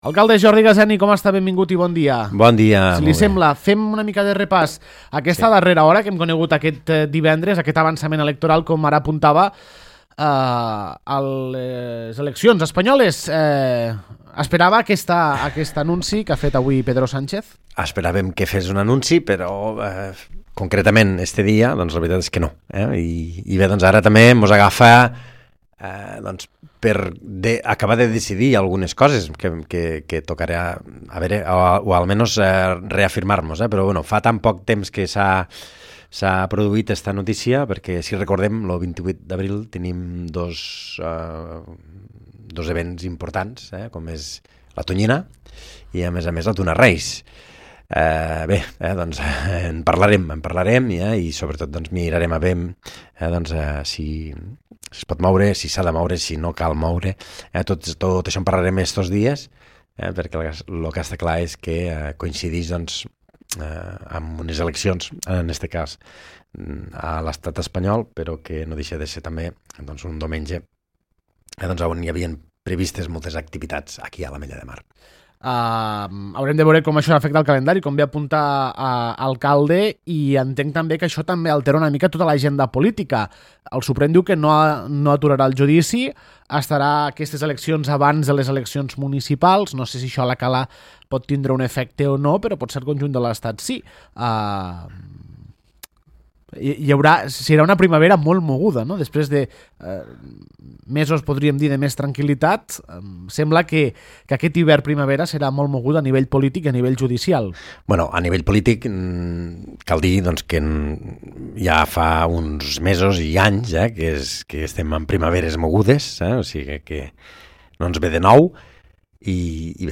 Jordi Gaseni, alcalde de l’Ametlla de Mar